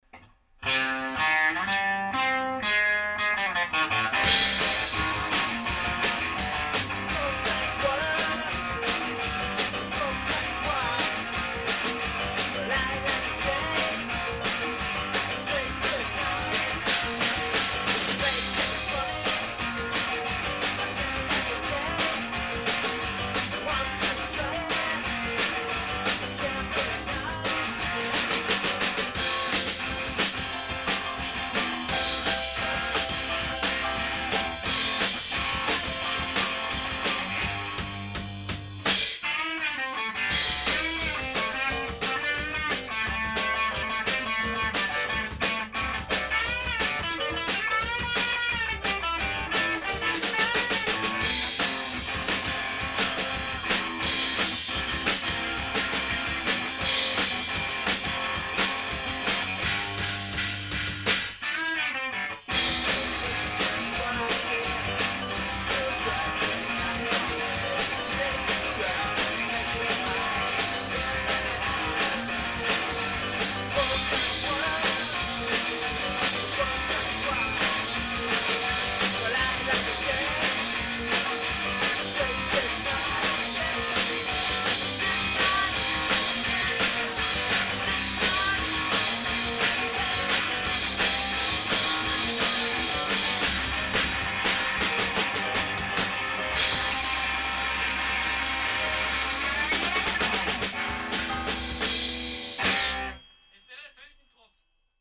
garage rock
aperçu rapide enregistré en répèt